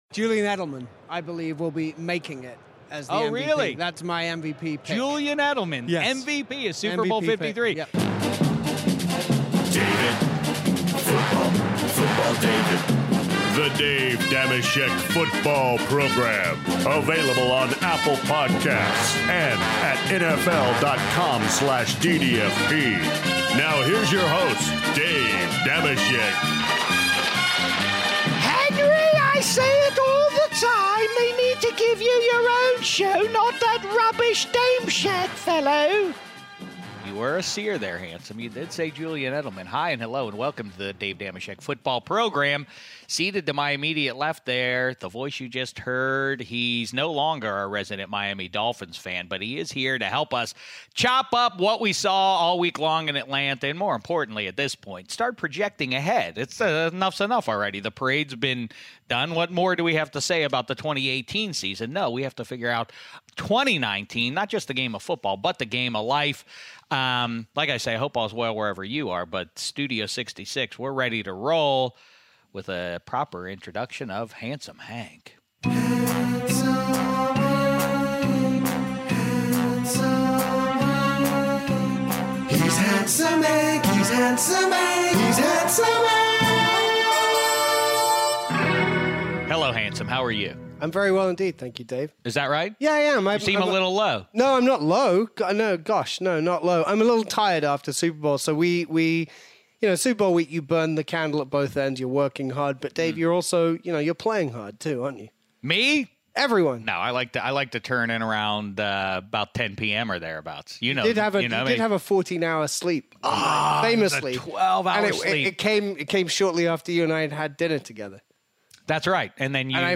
Finally, we hear from Atlanta Falcons QB Matt Ryan who sat down with Dave during Super Bowl week (52:03)!